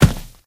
sounds / material / human / step / new_wood2.ogg
new_wood2.ogg